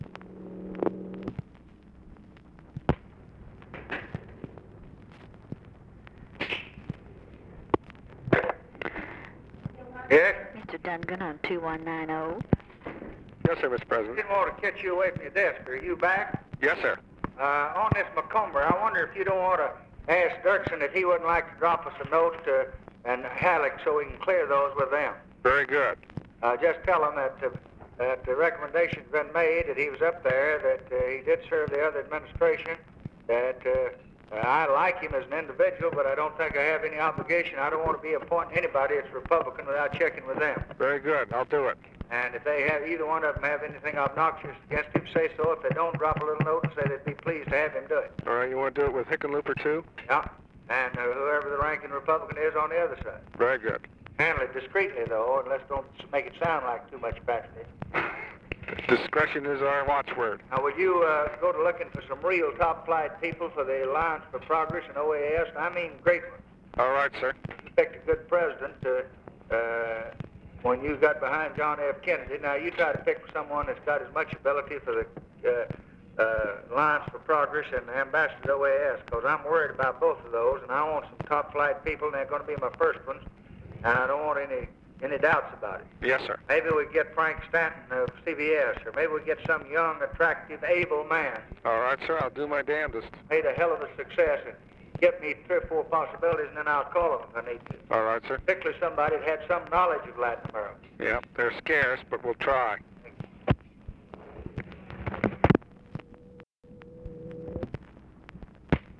Conversation with RALPH DUNGAN, December 4, 1963
Secret White House Tapes